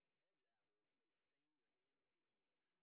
sp08_train_snr10.wav